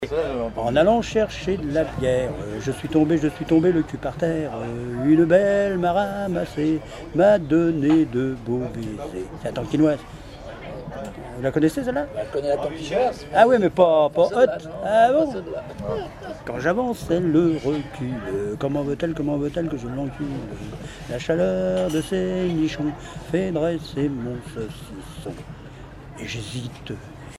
Chansons et commentaires
Pièce musicale inédite